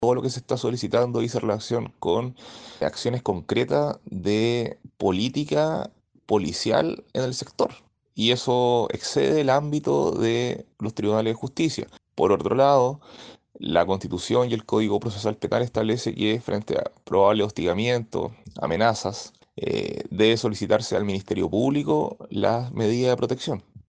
el abogado penalista